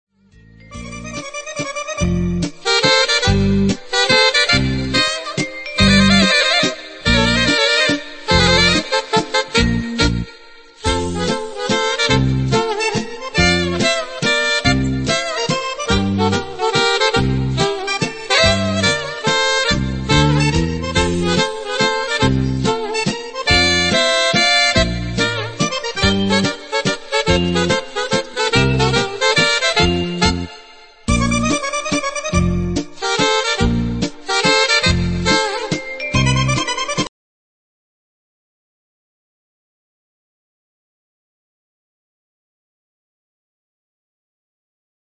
mazurca